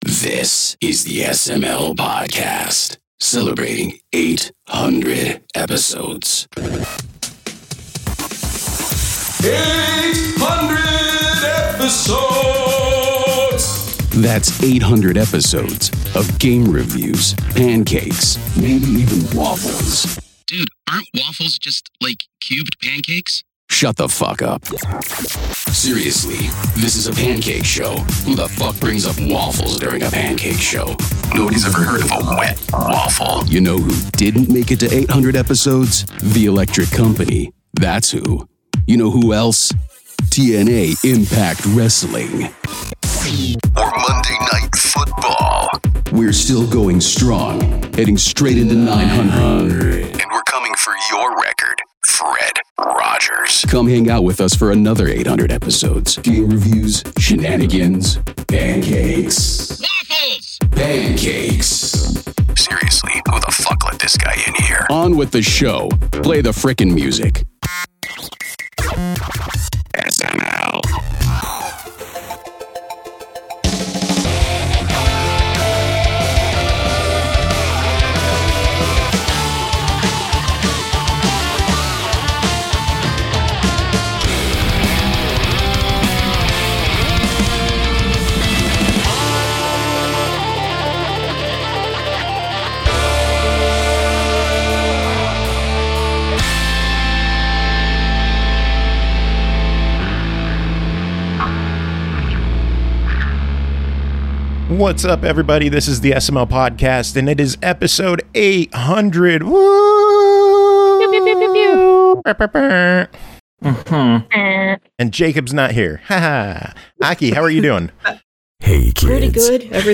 It’s a big ole PartyCast out of nowhere!